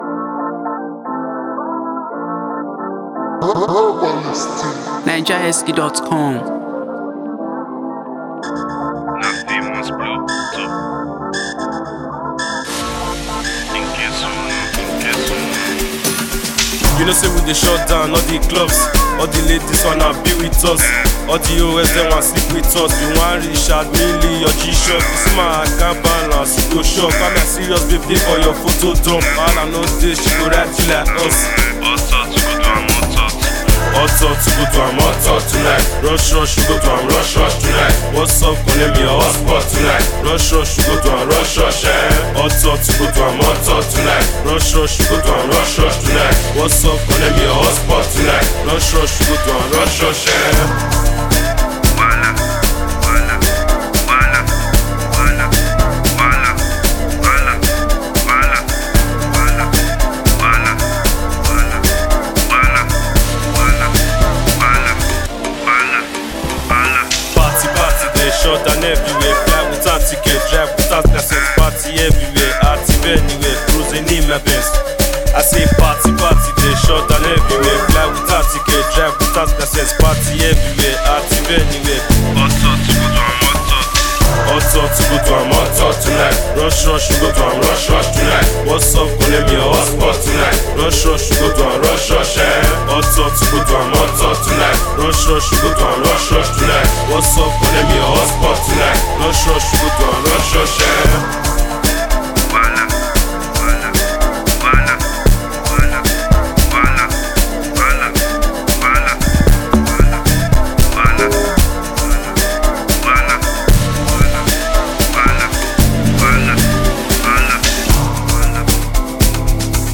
catchy song